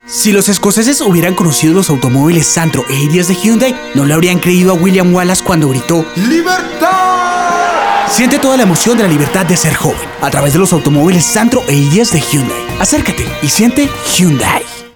voz con alta Versatilidad vocal; registros altos o bajos todo deacuerdo al cliente junto a la identidad del proyecto y la marca.
Sprechprobe: Industrie (Muttersprache):